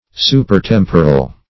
Search Result for " supertemporal" : The Collaborative International Dictionary of English v.0.48: Supertemporal \Su`per*tem"po*ral\, n. That which is more than temporal; that which is eternal.